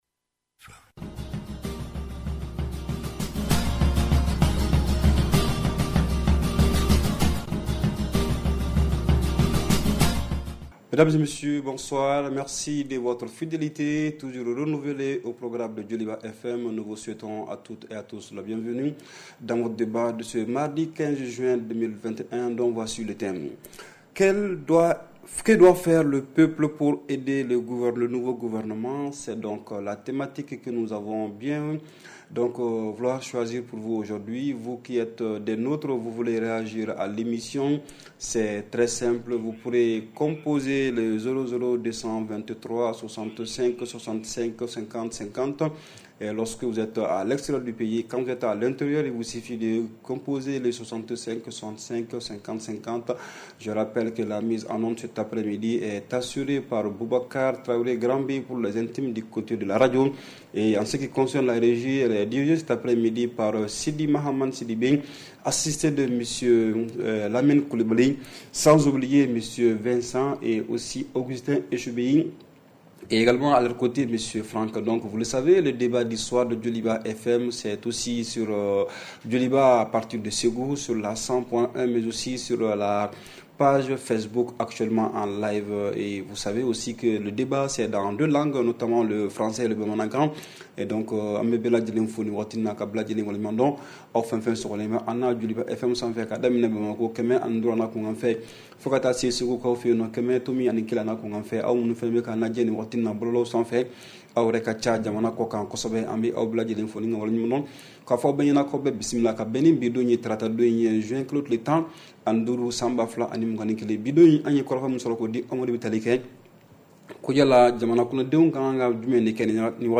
REPLAY 15/06 – « DIS ! » Le Débat Interactif du Soir